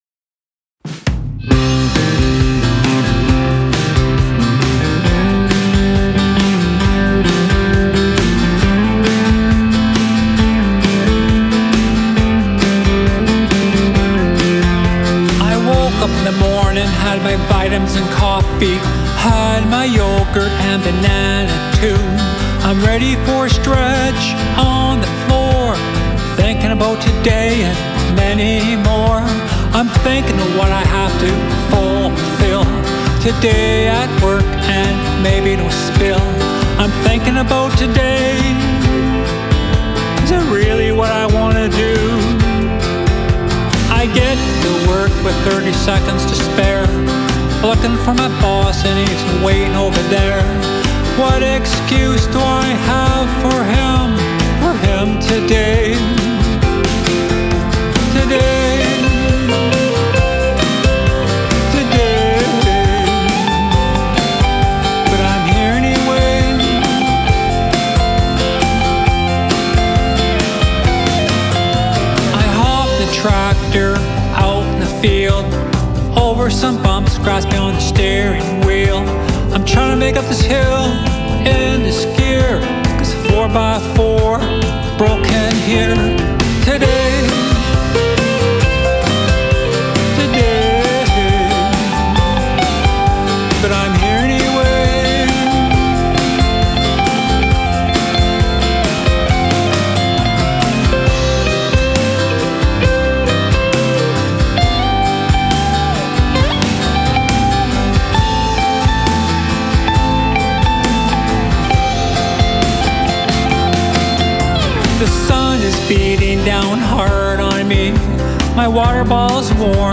Canadian singer/songwriter